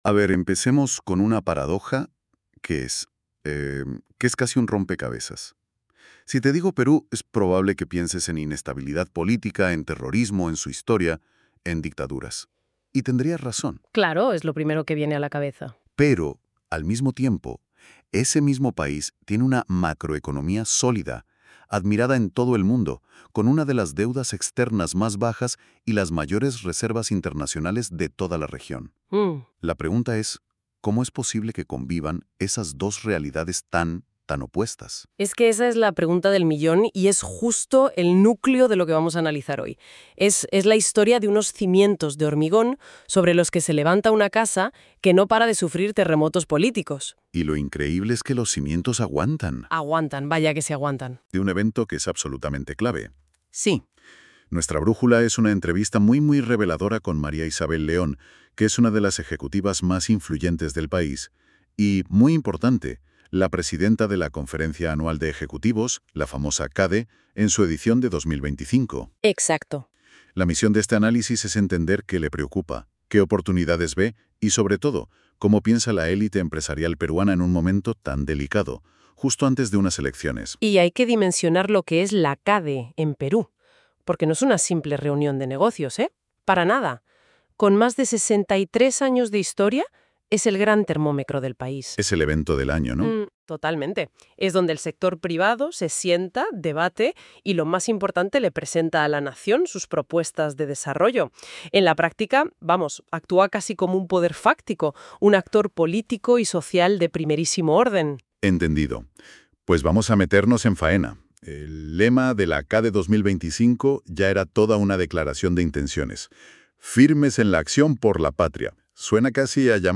Entrevista y Podcast | Revista Panorámica